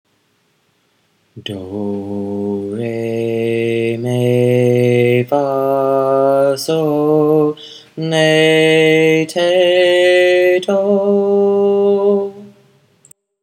In do-based minor, more syllables are added for sharps and flats, do continues to be the tonic in minor: do re me fa so le te do.
minor-do.m4a